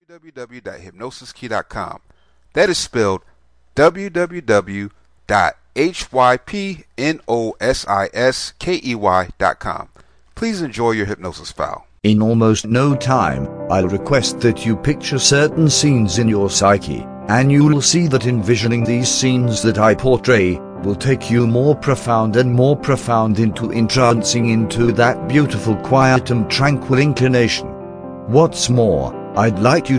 Patience Relaxation Self Hypnosis Mp3